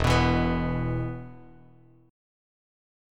E5 Chord
Listen to E5 strummed